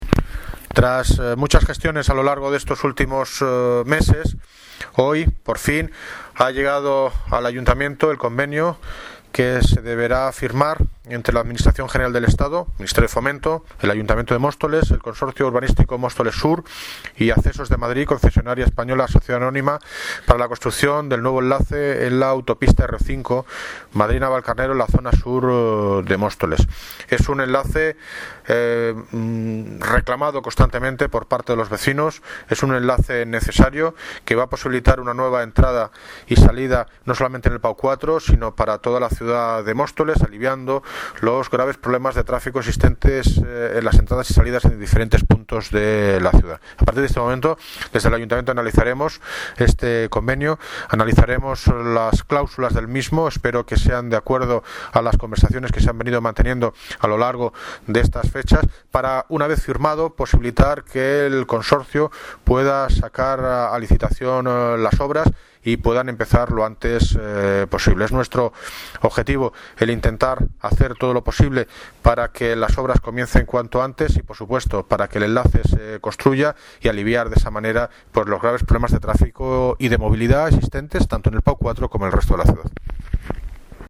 Audio - David Lucas (Alcalde de Móstoles) Sobre enlace a R-5